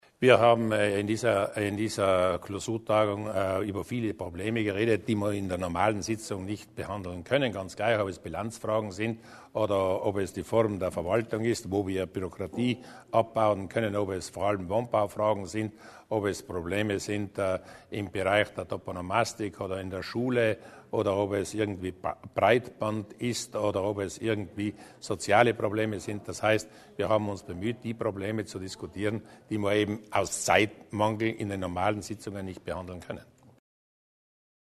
Landeshauptmann Durnwalder zu den wichtigsten Entscheidungen